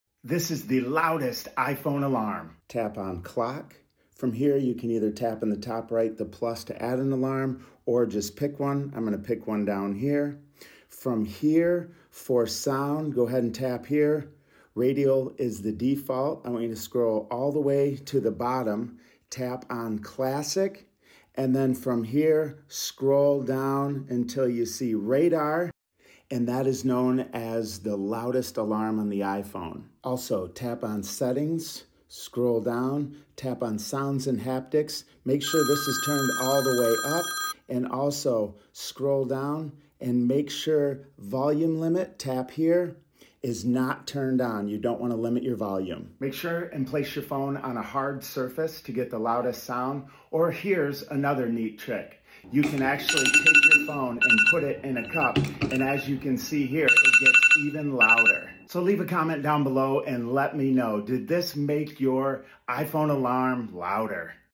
This is the loudest iPhone sound effects free download
This is the loudest iPhone alarm.